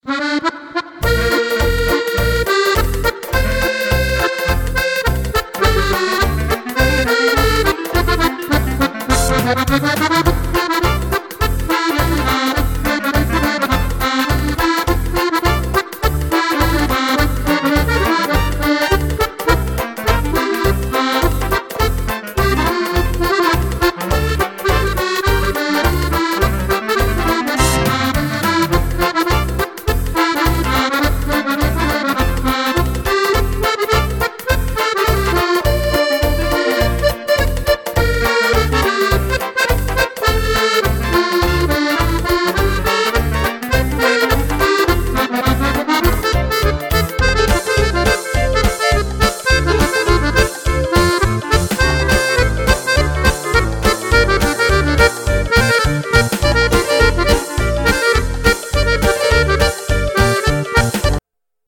(Charleston)